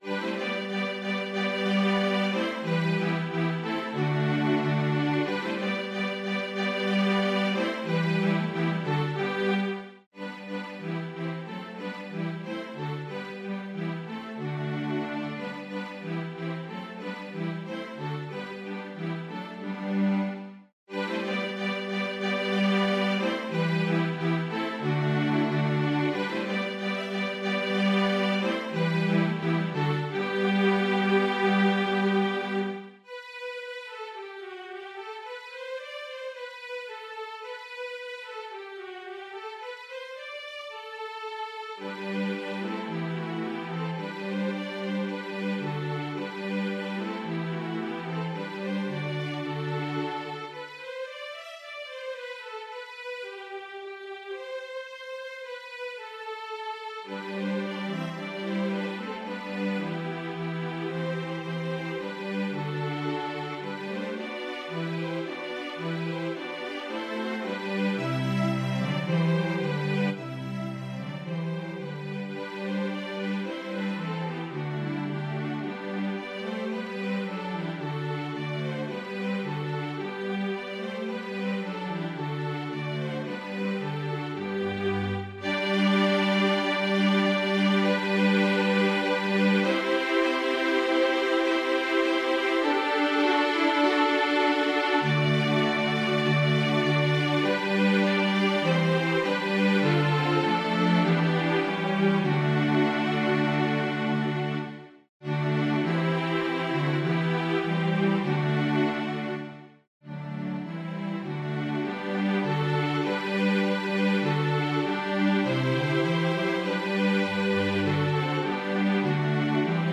String Orchestra
Brilliant fortes are contrasted with smooth lyrical playing.